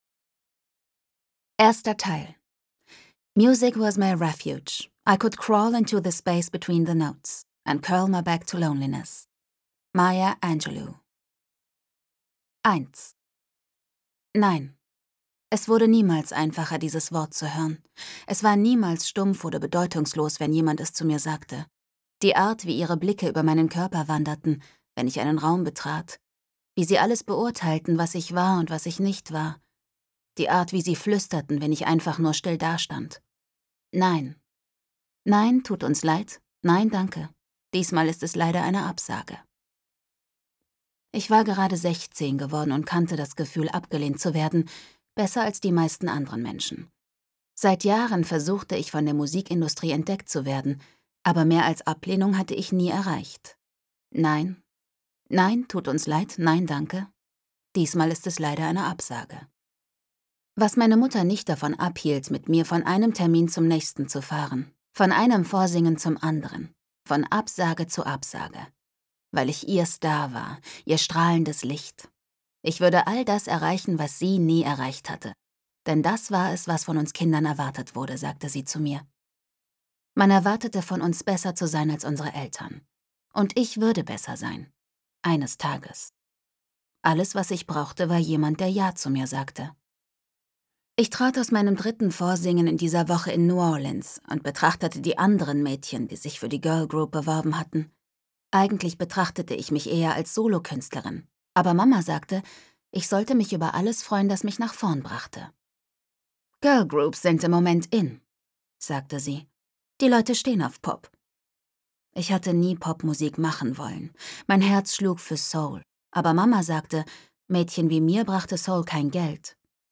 2019 | 1. ungekürzte Lesung